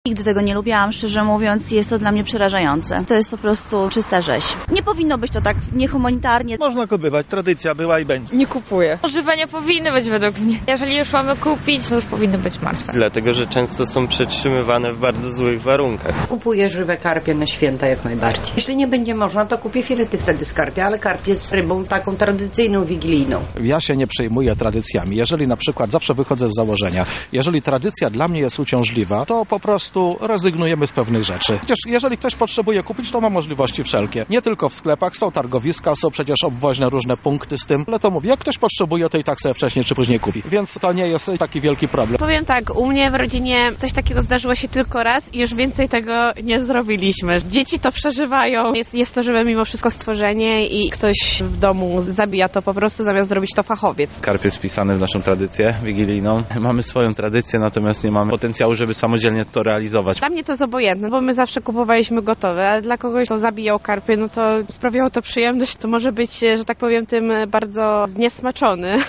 A co o tym sądzą mieszkańcy Lublina?